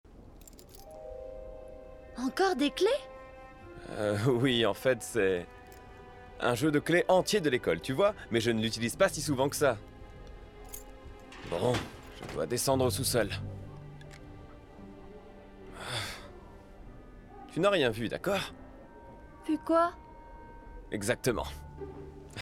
IMDA - Voix enfant - Christmas Break in extrait 3 -